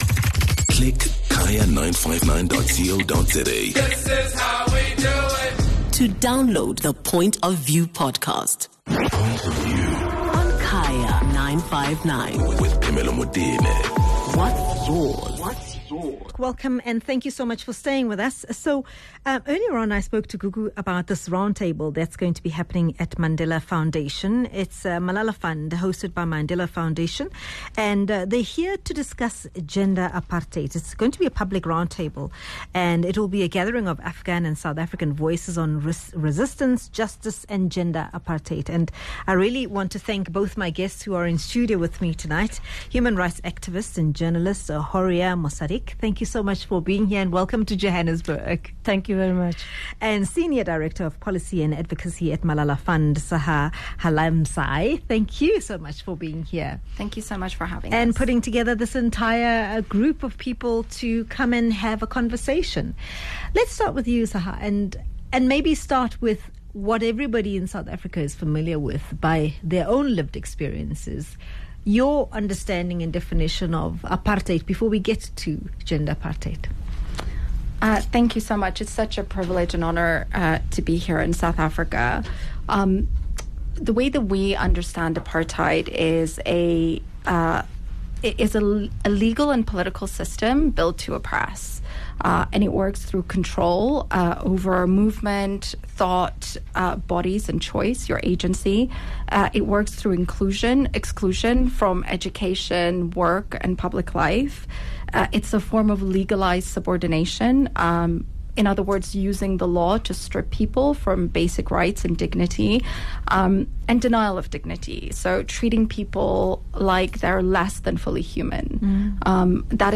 14 Apr Discussion: Public Roundtable on Gender Apartheid